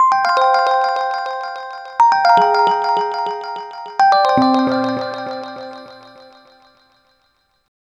Twinkle Water.wav